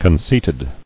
[con·ceit·ed]